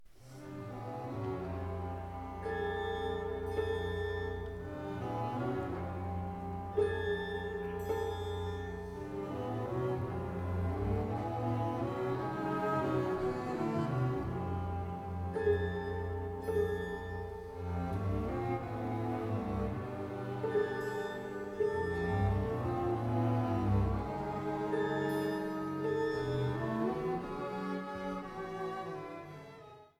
Andante molto